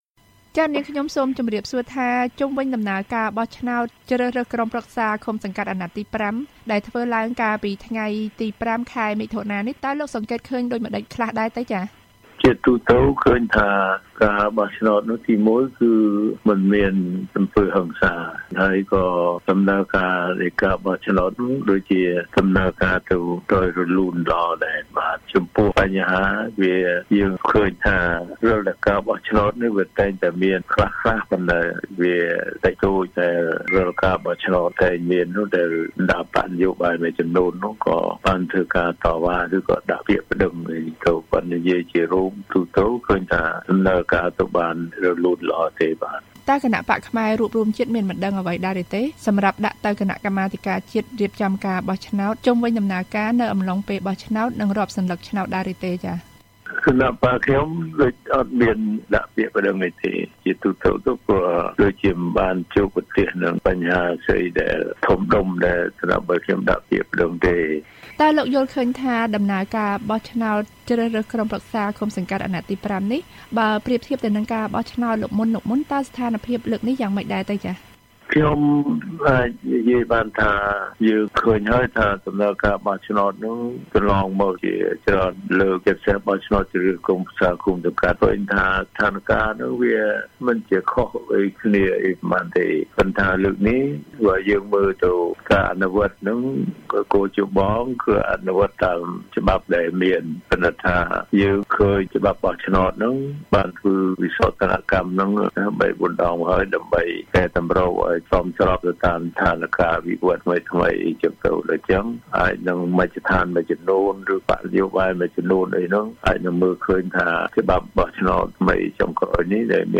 បានសម្ភាសលោក ញឹក ប៊ុនឆៃ ប្រធានគណបក្សខ្មែររួបរួមជាតិ